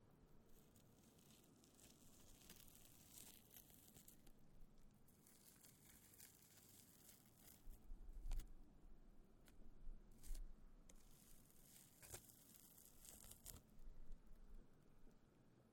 Pulling moss
Duration- 15Environment - Light breeze, mountains, open air, waterfall, rivers, and passing by cars.Description - Pulling, moss, breaking, tearing out of ground. stretching,